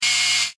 Photo_zoom.ogg